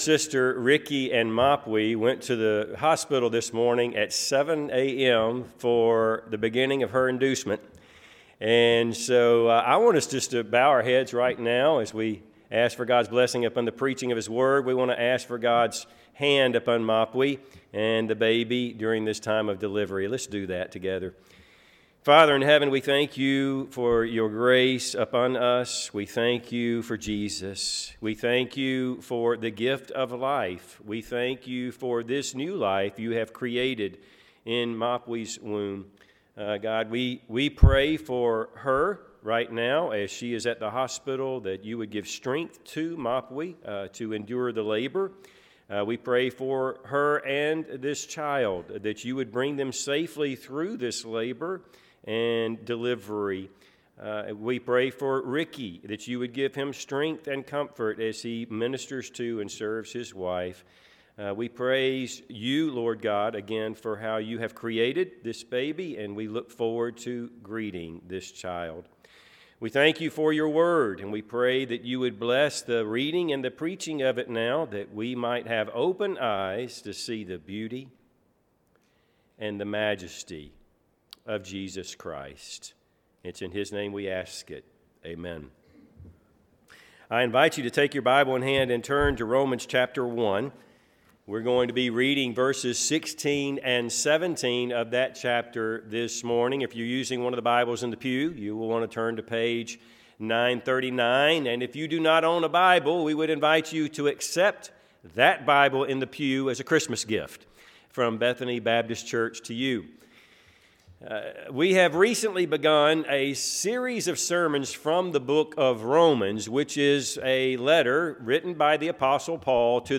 Passage: Romans 1:16-17 Service Type: Sunday AM